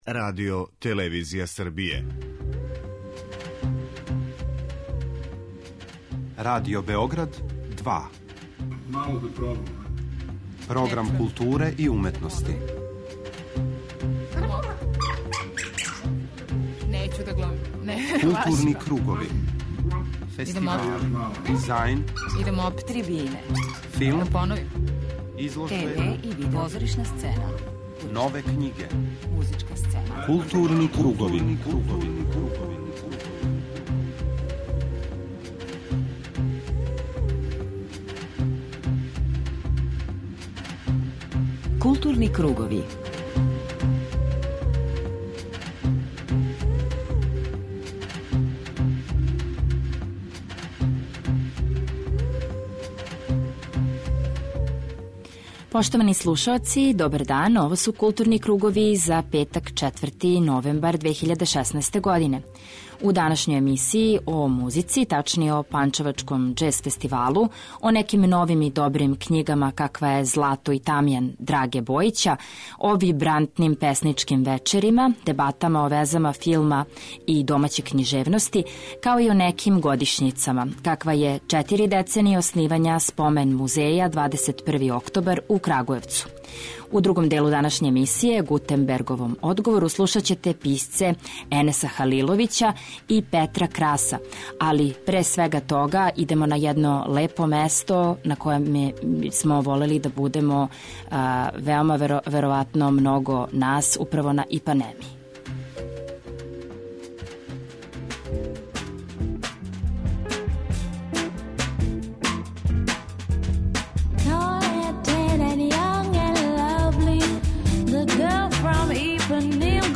У разговору са писцем сазнаћемо о настанку овог романа са поднасловом "дијалог о празнини", о формалном двогласју приче (филозофском и свакодневном), о питањима кривице и слободе...
преузми : 41.12 MB Културни кругови Autor: Група аутора Централна културно-уметничка емисија Радио Београда 2.